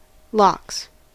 Ääntäminen
Vaihtoehtoiset kirjoitusmuodot LOX LOx Synonyymit smoked salmon salmon Ääntäminen US US : IPA : /lɑks/ Haettu sana löytyi näillä lähdekielillä: englanti Käännöksiä ei löytynyt valitulle kohdekielelle.